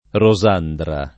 [ ro @# ndra ]